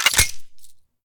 select-machine-gun-2.ogg